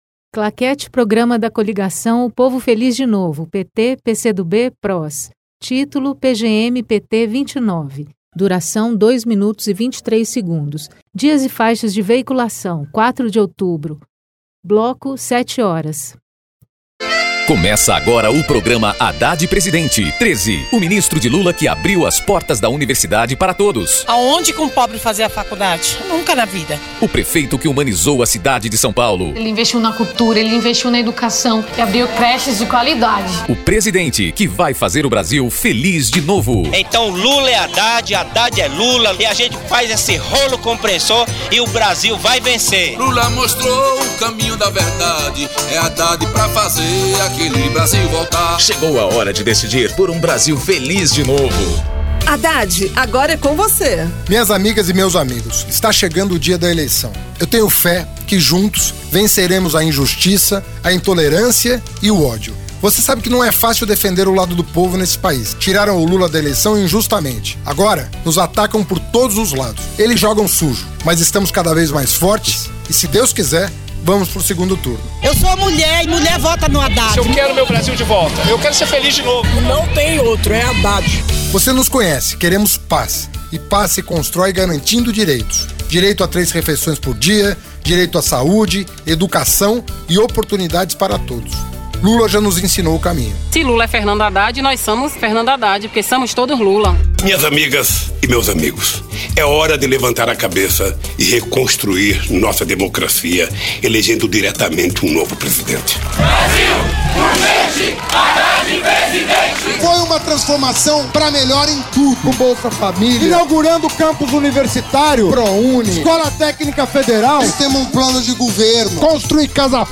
TítuloPrograma de rádio da campanha de 2018 (edição 29)
Gênero documentaldocumento sonoro